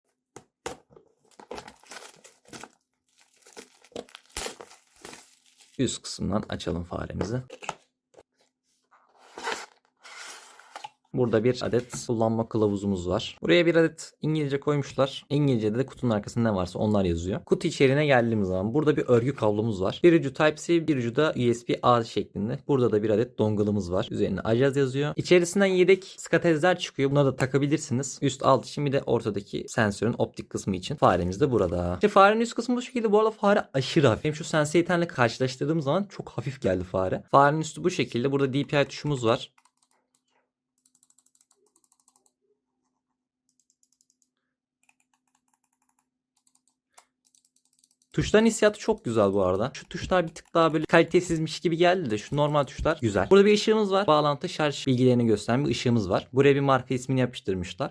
Ajazz AJ139 Pro Kutu Açılışı sound effects free download